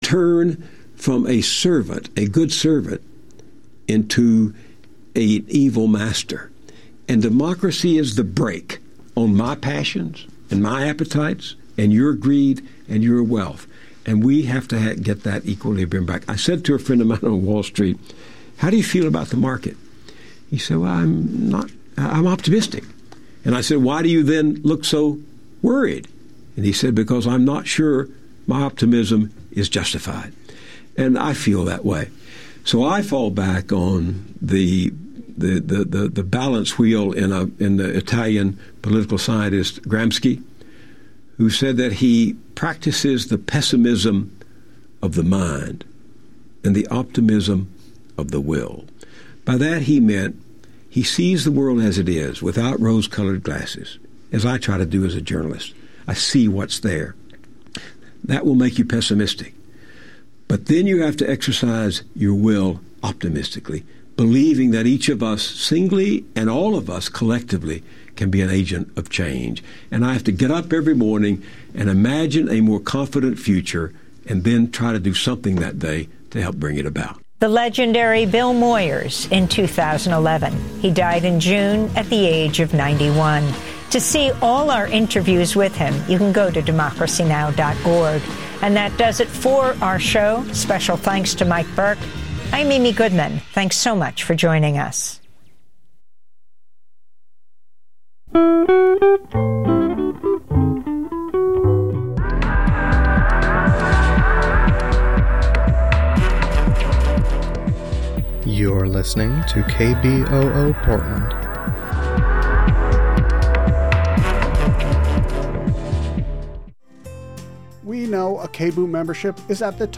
Friday Talk Radio